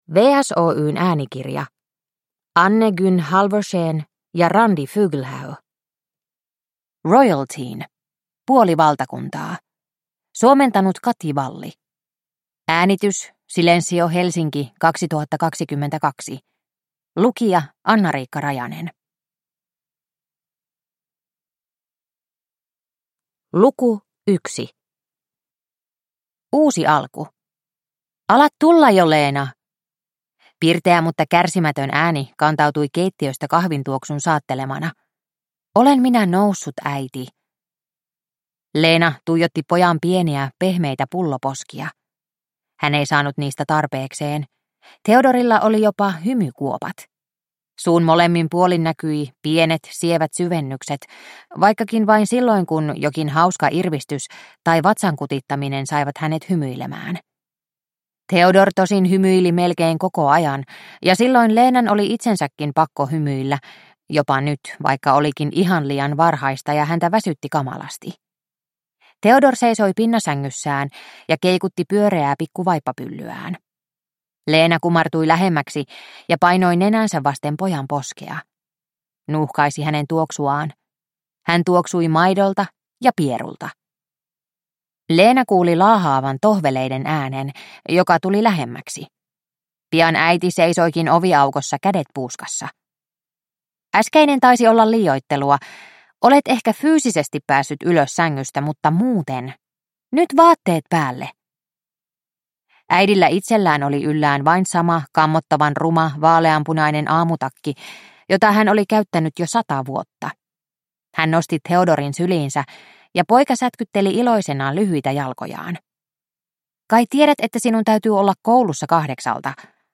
Royalteen 1: Puoli valtakuntaa – Ljudbok